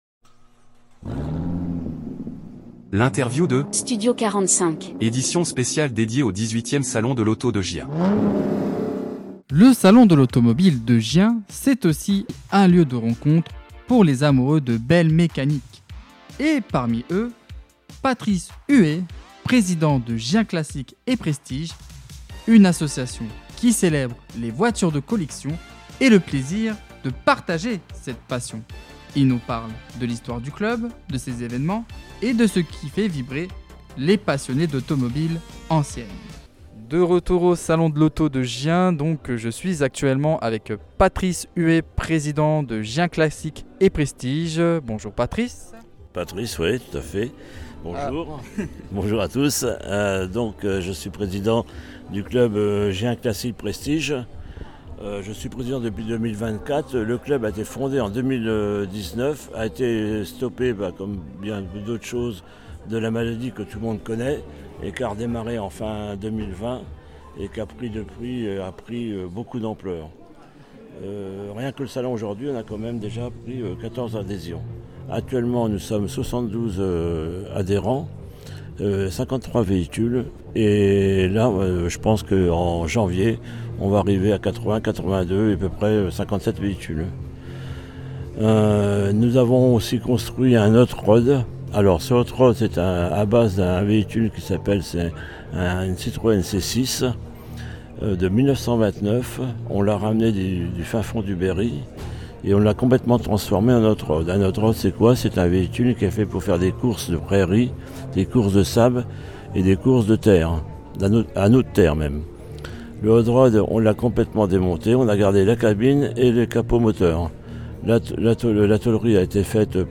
Interview Studio 45 - édition spéciale Salon de l'Auto Gien 2025 - Gien, Classic & Prestige